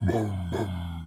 Audio / SFX / Characters / Voices / LegendaryChef